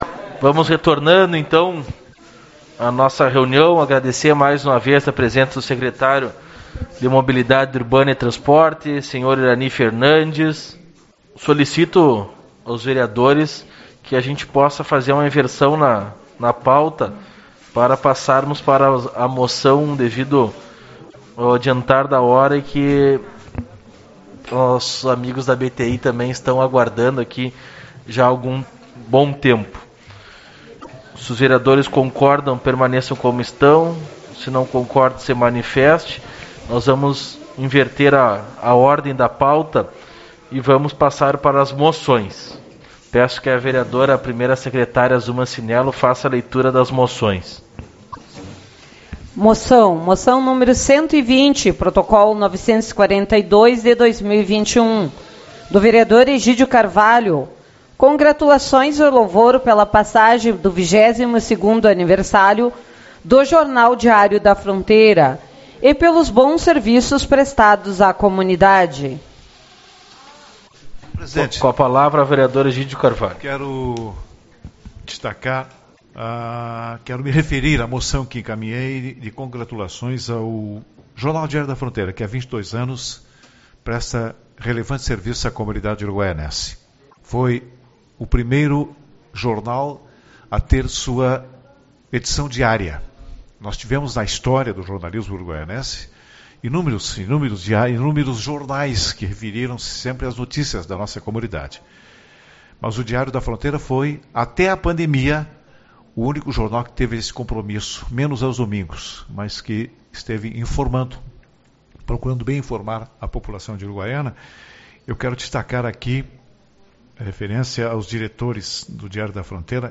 09/09 - Reunião Ordinária